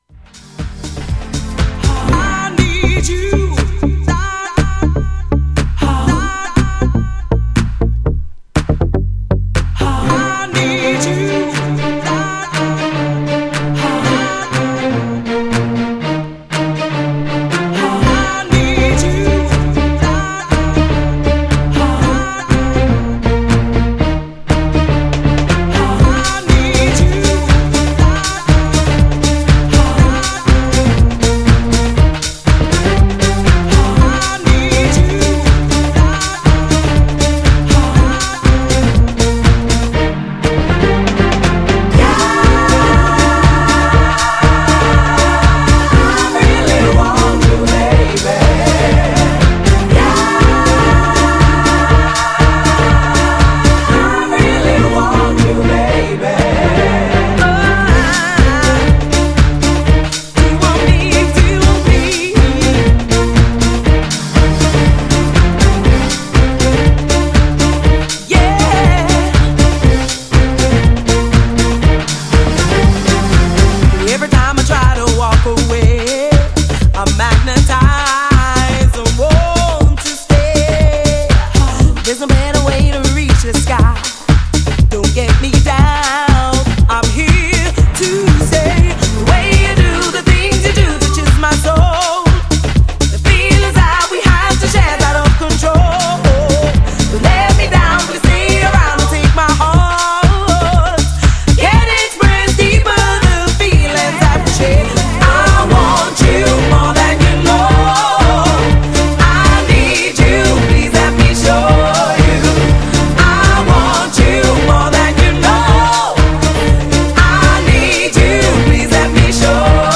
メロディアスなキーに優しい歌声が華麗にMIXされるUK R&B。
GENRE R&B
BPM 91〜95BPM